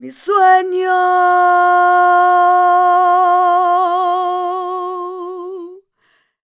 46b03voc-f.wav